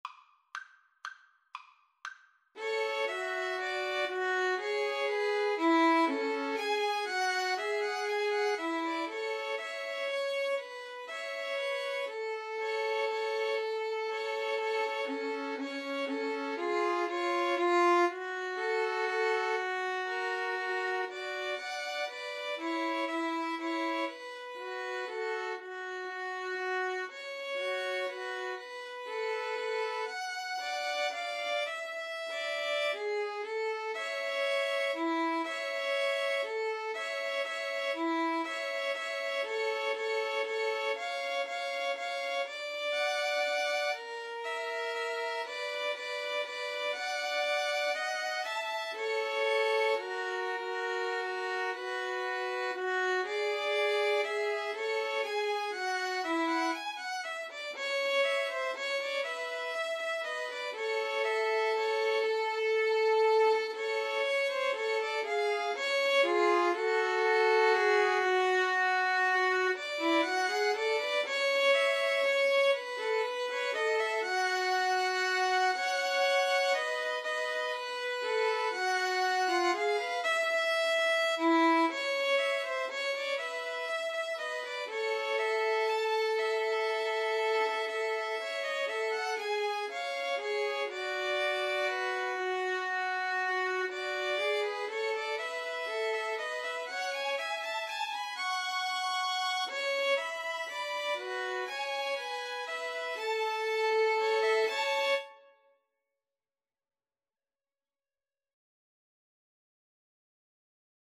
Free Sheet music for Violin Trio
A major (Sounding Pitch) (View more A major Music for Violin Trio )
= 120 Tempo di Valse = c. 120
3/4 (View more 3/4 Music)